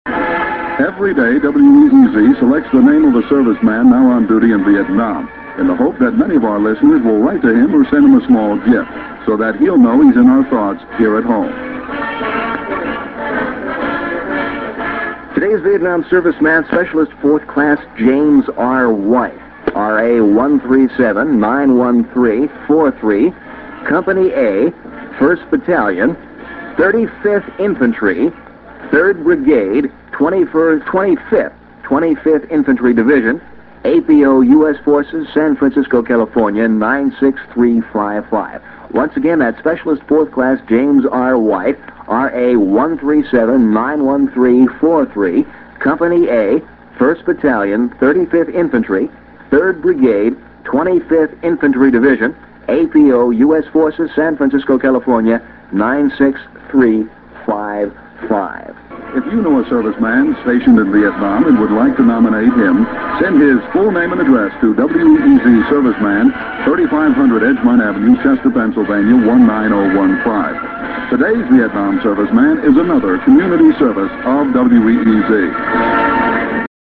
While the quality is not up to broadcast standards, it is the only copy available.